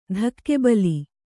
♪ dhakke ali